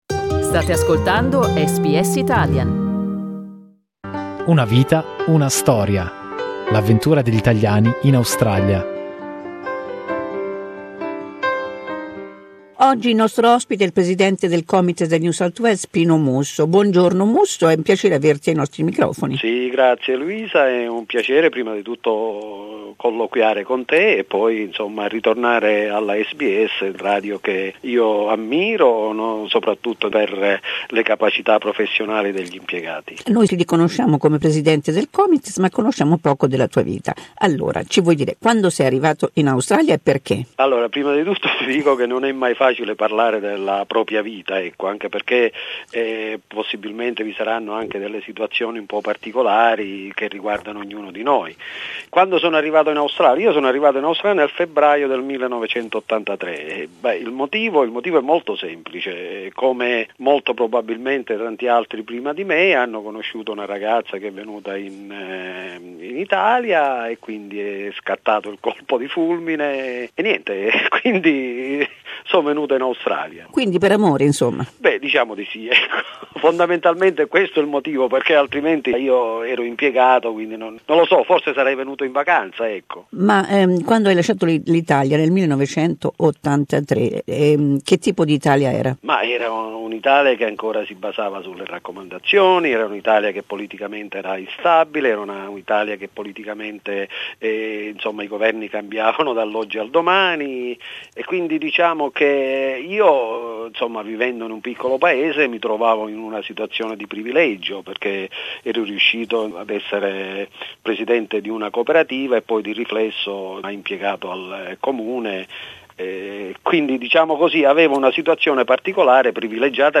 SBS Italian lo ricorda con questa intervista del 2016.